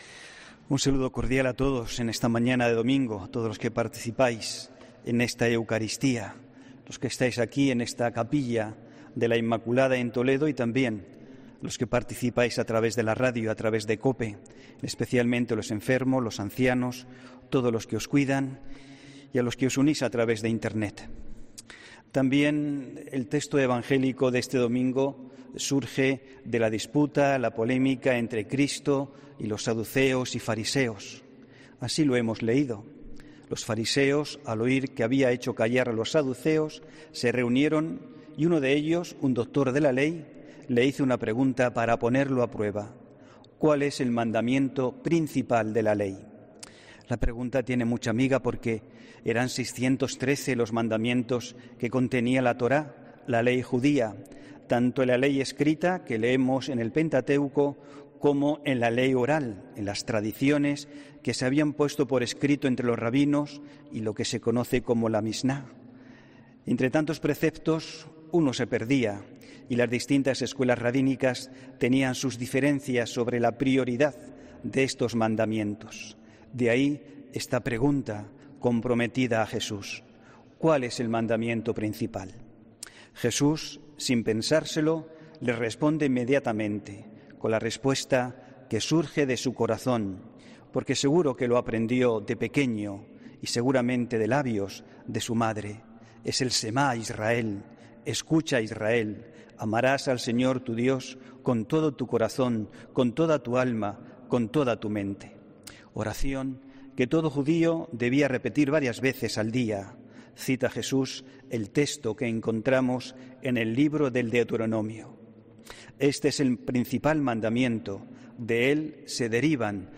AUDIO: HOMILÍA 25 OCTUBRE 2020